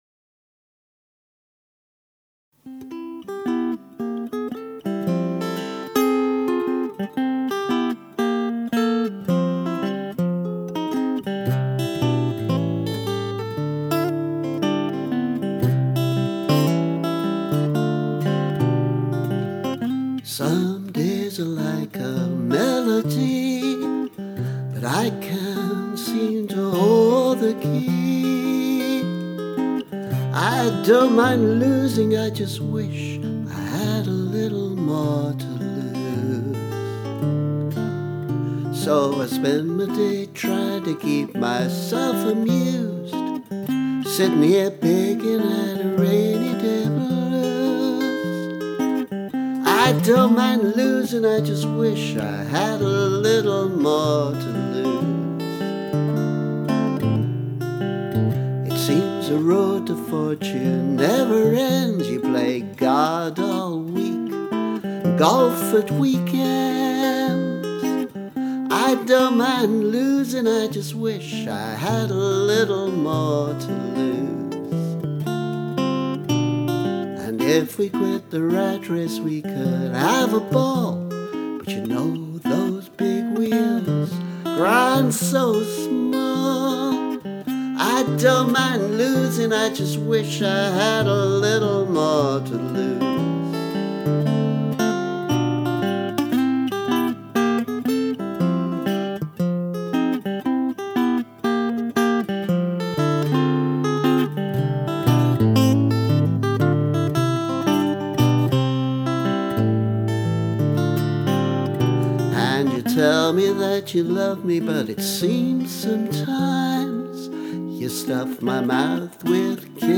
A rainy day blues [demo]